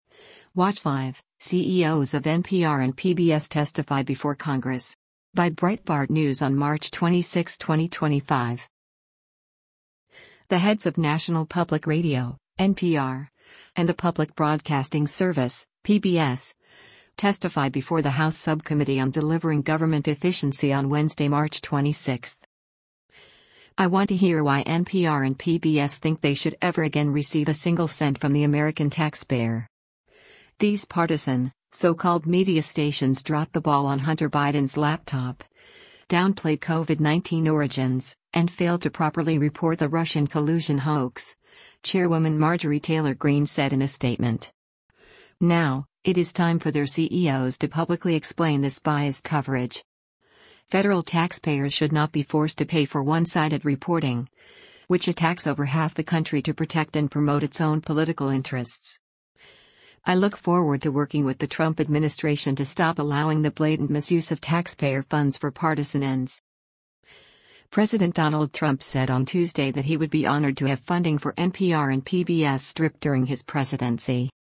The heads of National Public Radio (NPR) and the Public Broadcasting Service (PBS) testify before the House Subcommittee on Delivering Government Efficiency on Wednesday, March 26.